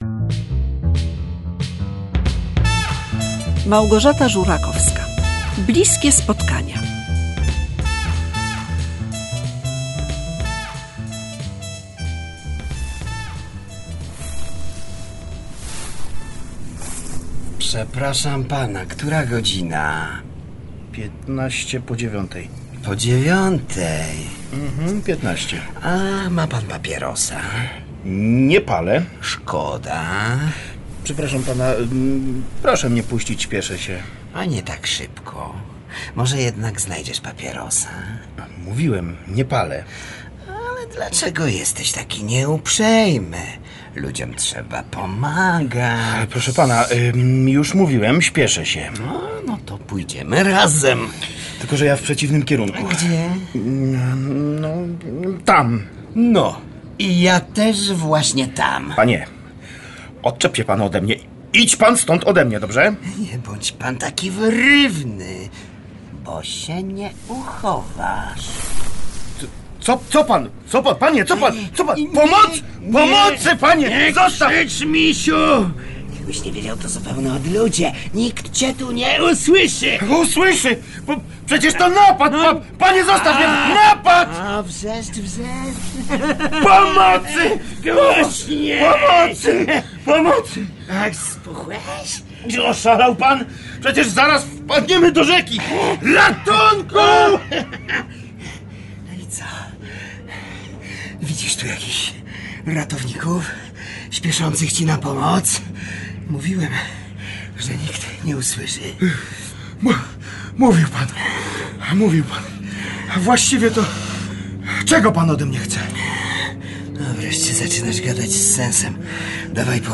W słuchowisku „Bliskie spotkania” o tym, jakie konsekwencje może mieć napad rabunkowy, szczególnie, jeśli przez przypadek jest skierowany wobec niezbyt zasobnego bibliotekarza?